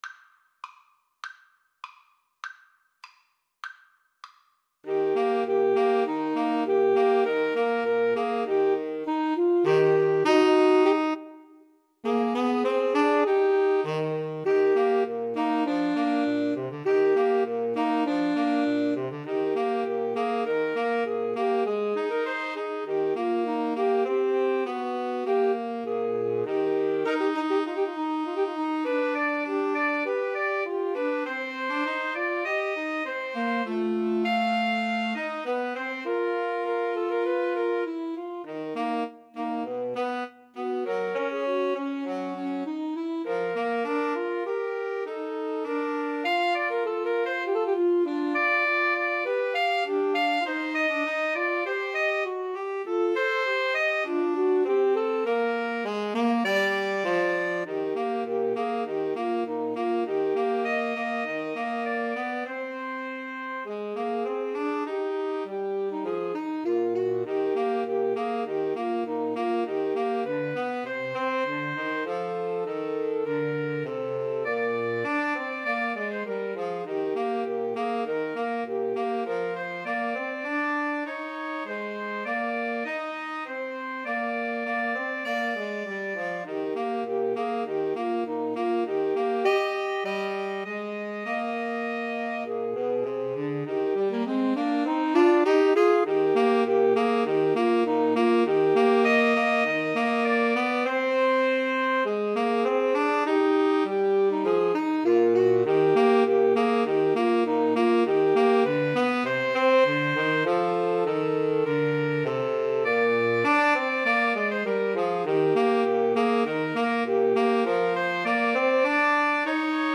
Soprano SaxophoneAlto SaxophoneTenor Saxophone
2/4 (View more 2/4 Music)
Tempo di Marcia
Pop (View more Pop Woodwind Trio Music)